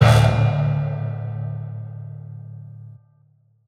Metro Hits [Brass X Orch].wav